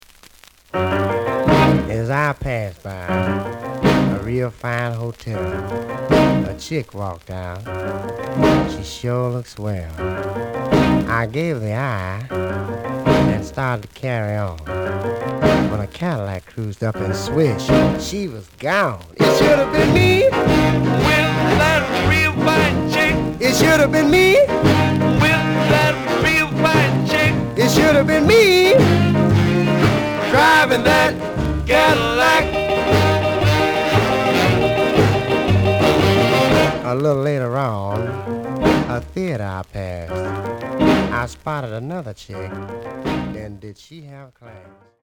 The audio sample is recorded from the actual item.
●Format: 7 inch
●Genre: Rhythm And Blues / Rock 'n' Roll
Some noise on A side.)